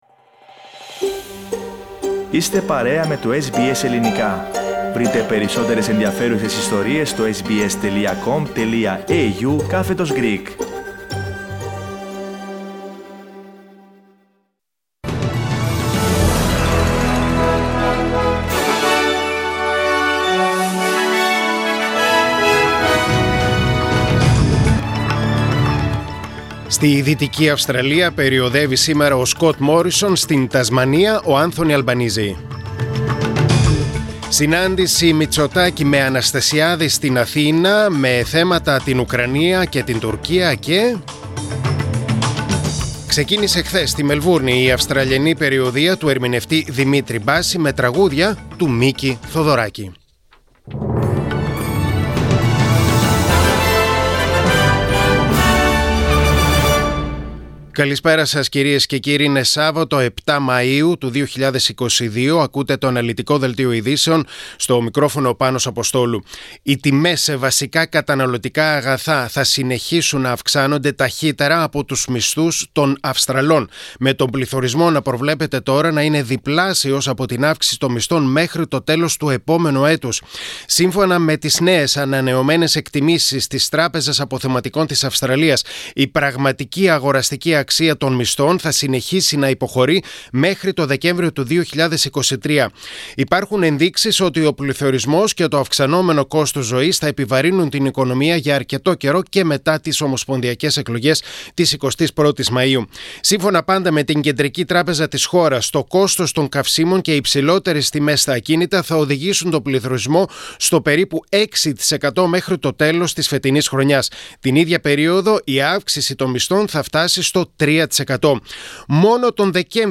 Δελτίο Ειδήσεων: Σάββατο 7.5.2022